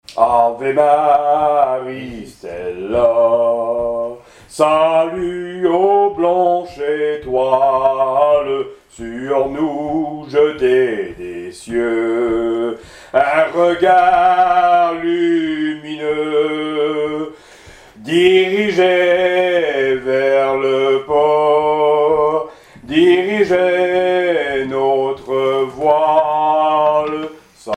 circonstance : cantique
Témoignage et chansons maritimes
Pièce musicale inédite